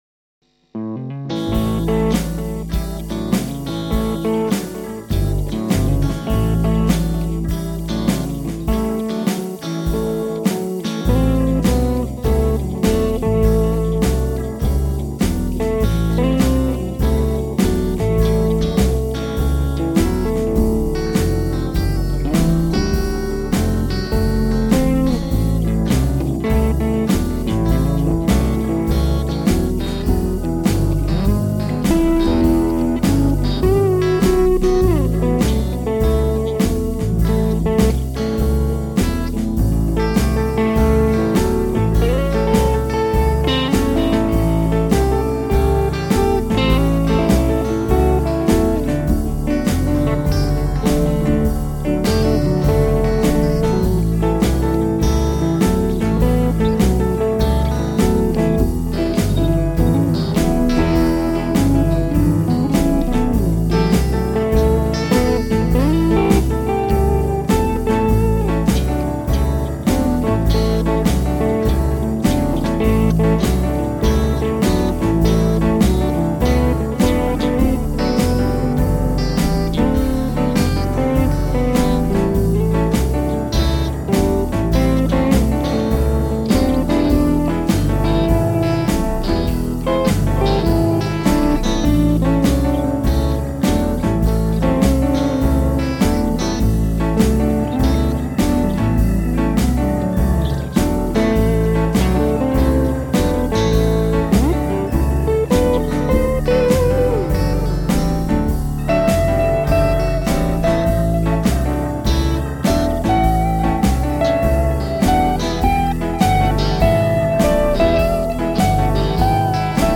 blues written and recorded today 17th march